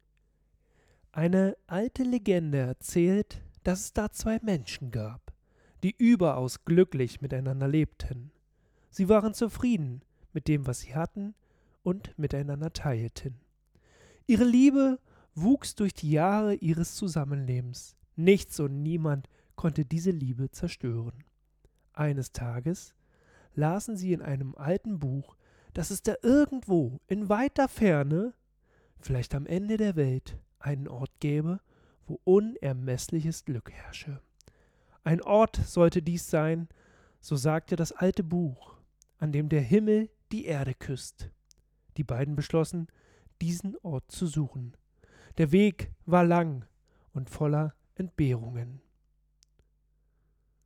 Redeproben:
Sprechprobe-kurz.mp3